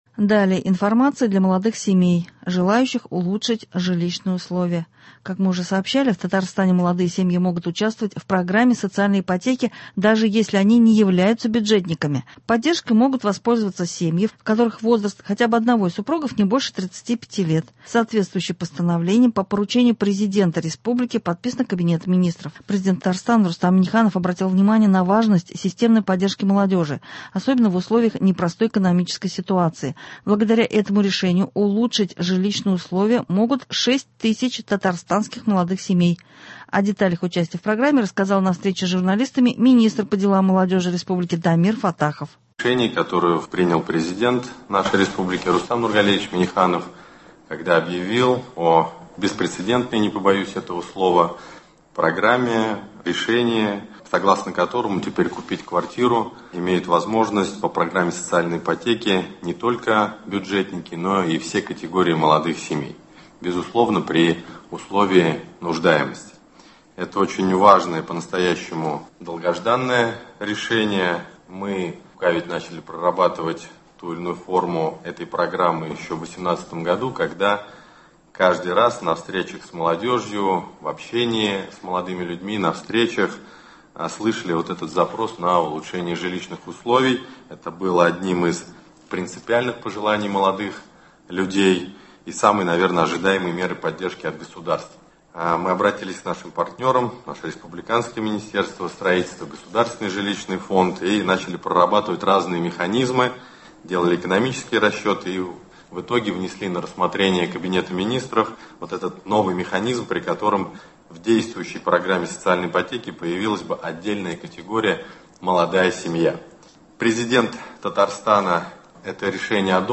О деталях участия в программе рассказал на встрече с журналистами министр по делам молодёжи республики Дамир Фаттахов.